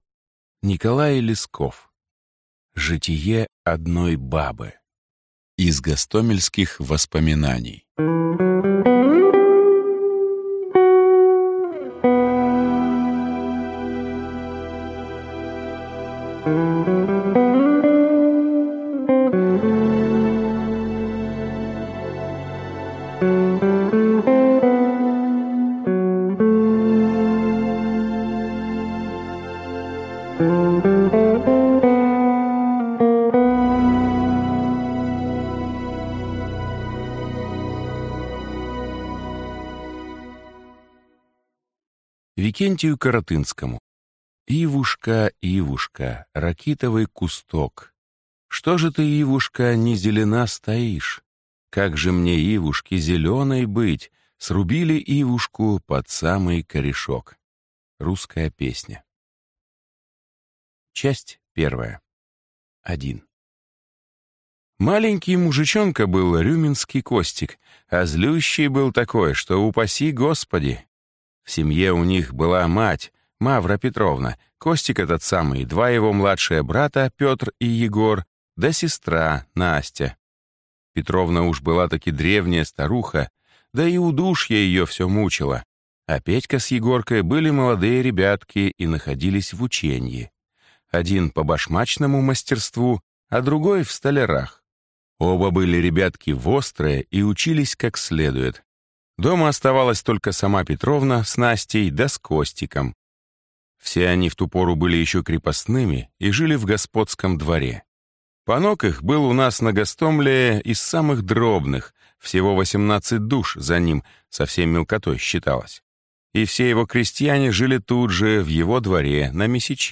Аудиокнига Житие одной бабы | Библиотека аудиокниг